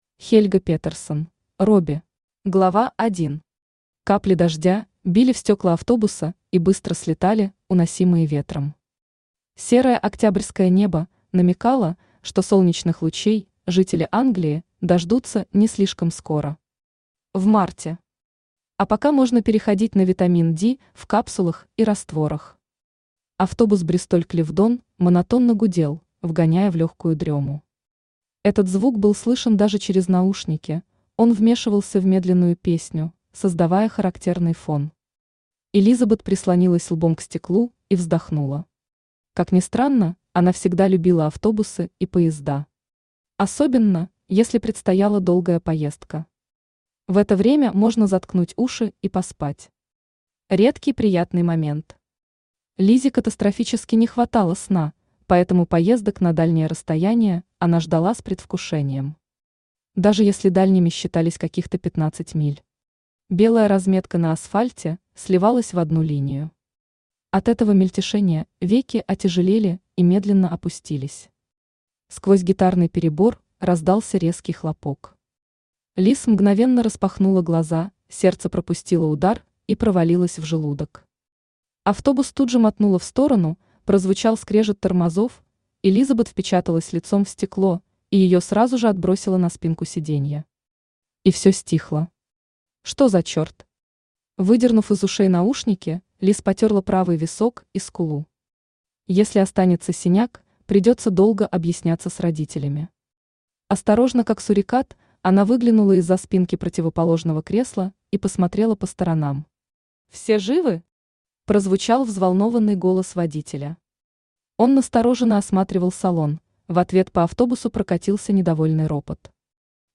Аудиокнига Робби | Библиотека аудиокниг
Aудиокнига Робби Автор Хельга Петерсон Читает аудиокнигу Авточтец ЛитРес.